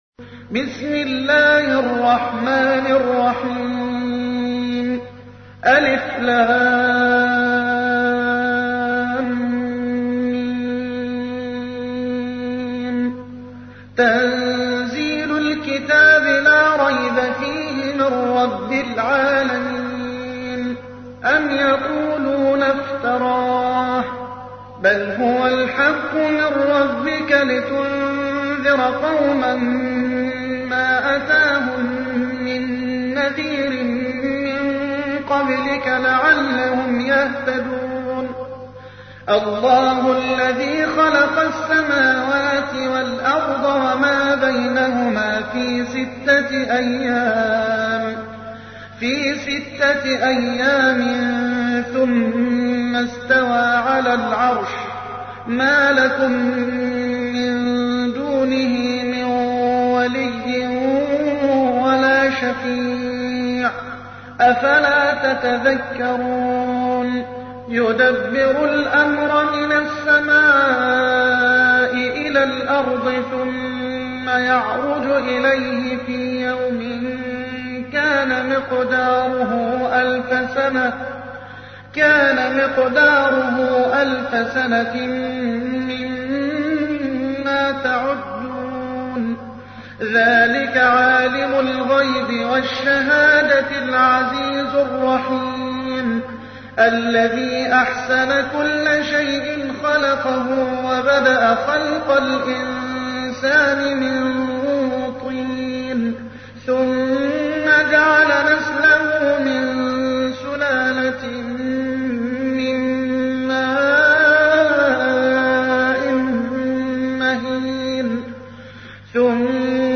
تحميل : 32. سورة السجدة / القارئ محمد حسان / القرآن الكريم / موقع يا حسين